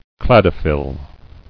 [clad·o·phyll]